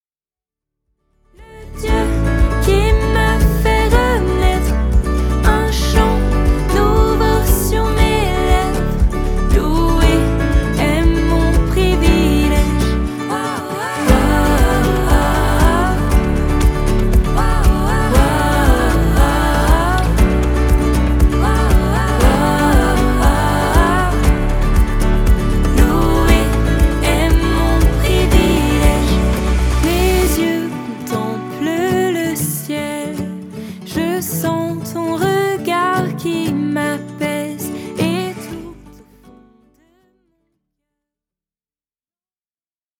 avec des mélodies apaisantes et une louange réconfortante.
ce sont 4 titres frais, doux et joyeux